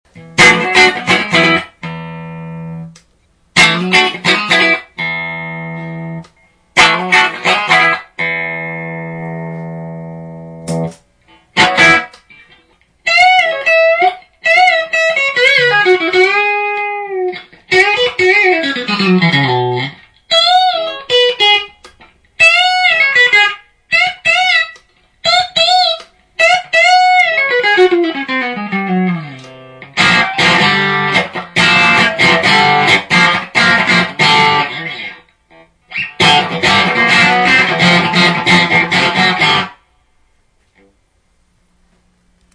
ギターからモガミのシールドケーブル(5m・スイッチプラグ付)→Carl MartinのHot Drive'n Boost→Whirlwindのシールドケーブル(1m)→Two-Rock Topazの順です。
今度はクリーンのままでフロント・ミックス・リア、その後ドライヴで同様に弾いてます。